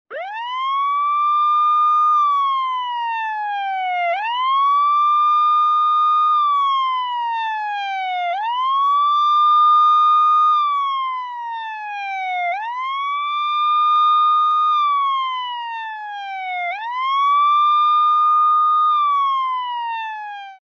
PoliceWhistle.mp3